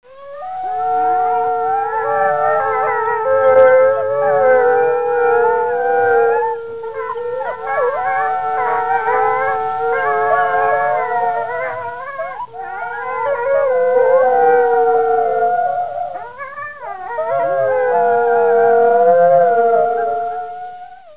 sef27wolves.wav